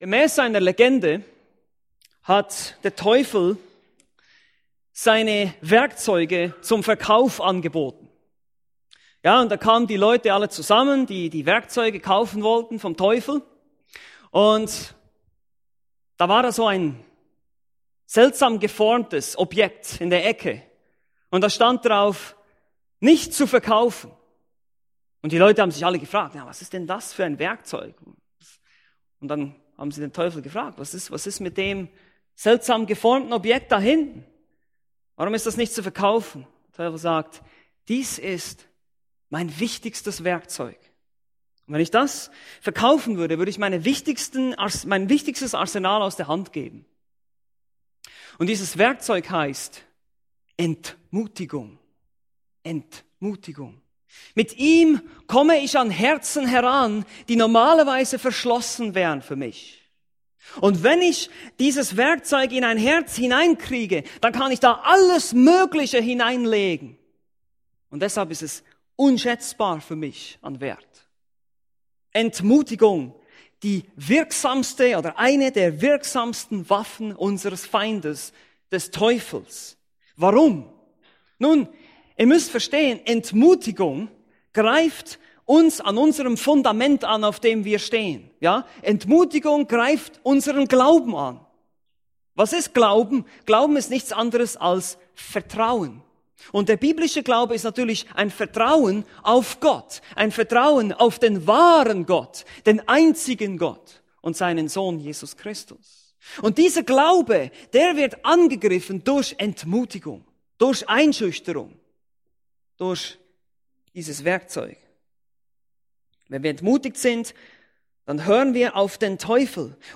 Predigten Übersicht nach Serien - Bibelgemeinde Berlin